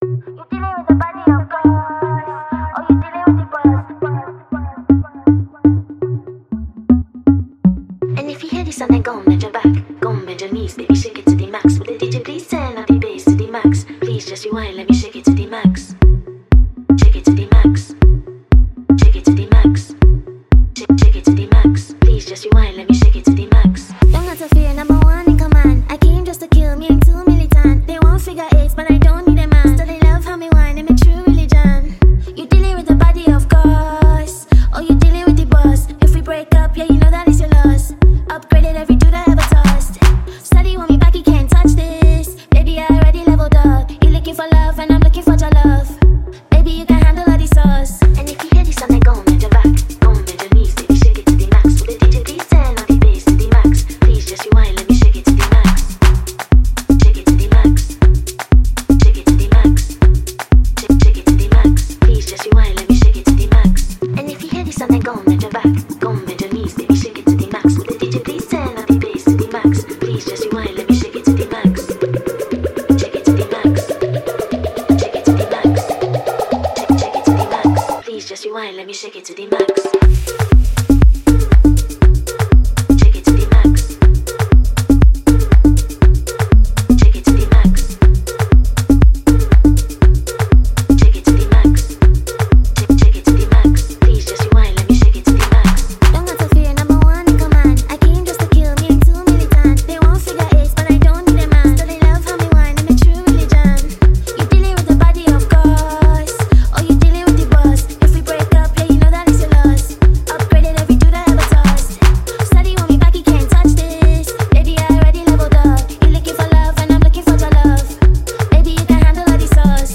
Gifted Ghanaian-American singer, songwriter and actress